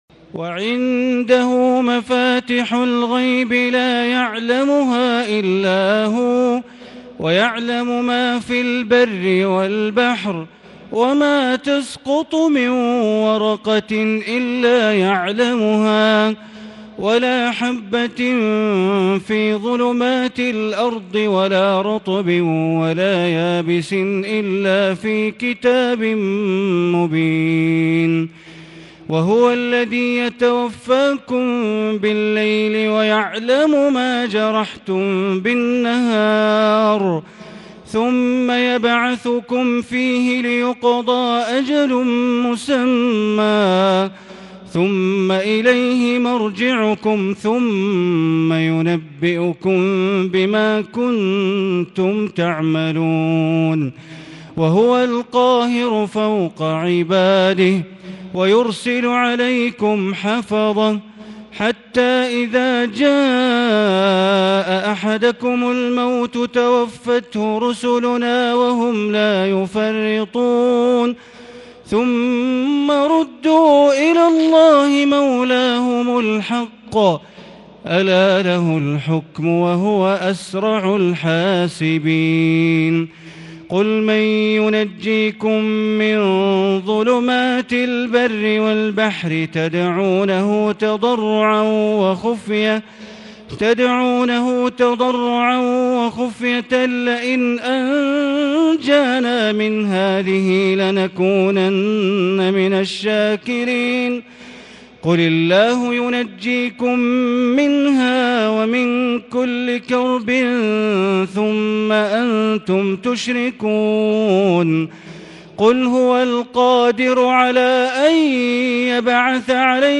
تهجد ليلة ٢٧ رمضان 1440 من سورة الأنعام اية ٥٩ إلى ١١١ اية > تراويح ١٤٤٠ هـ > التراويح - تلاوات بندر بليلة